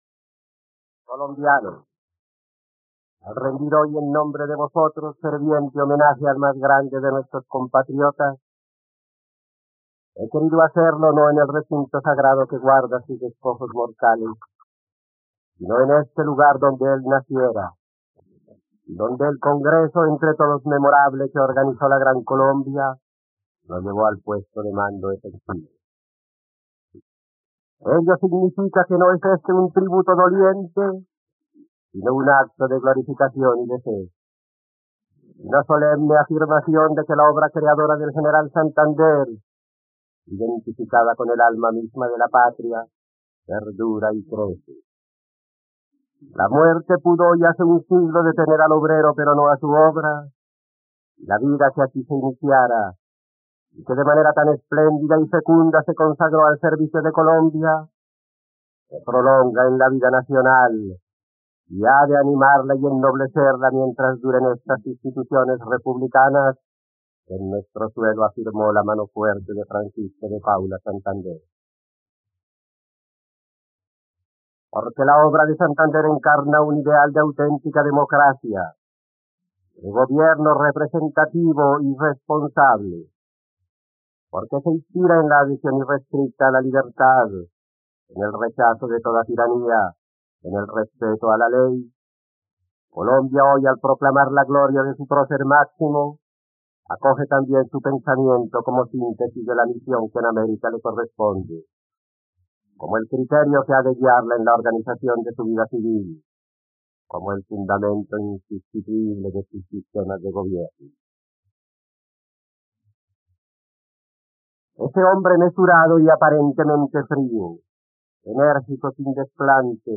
..Escucha ahora el discurso de Eduardo Santos en el centenario de la muerte de Francisco de Paula Santander, el 6 de mayo de 1940, en RTVCPlay.
discurso político